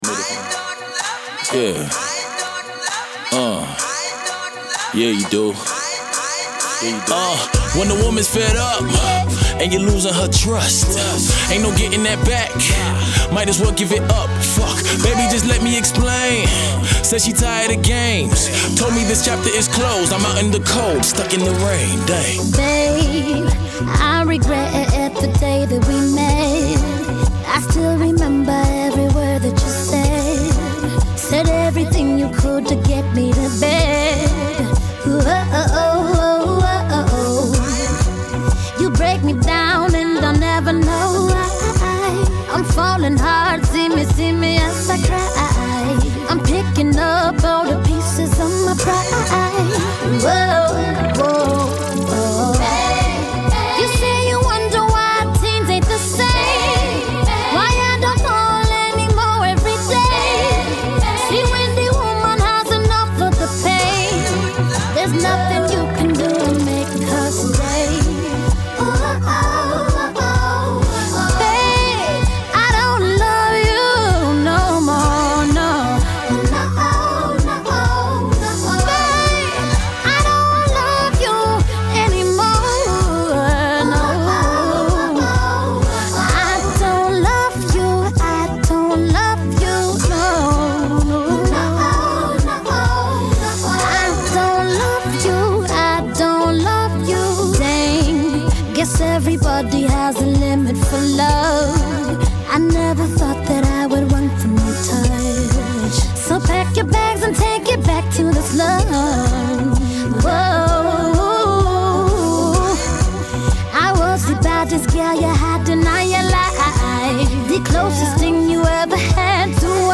a smooth reggae R&B heartbreak track